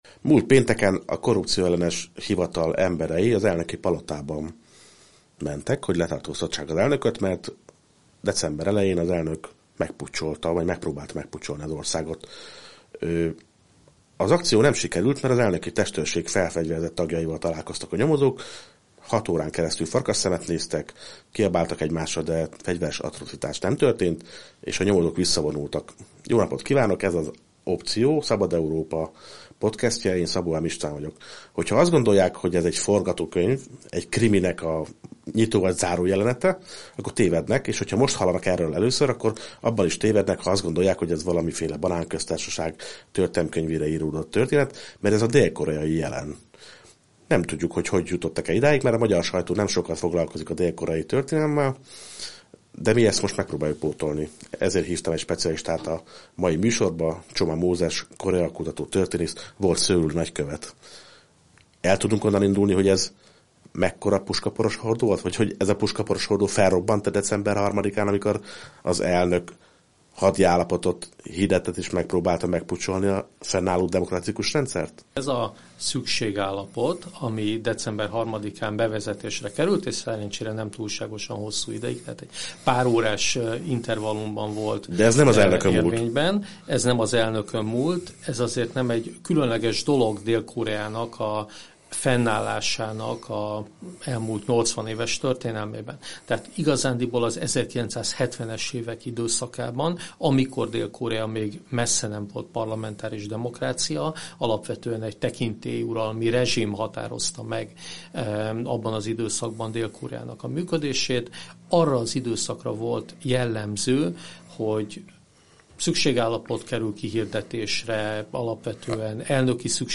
A helyzetről kérdeztük Csoma Mózes volt szöuli nagykövetet.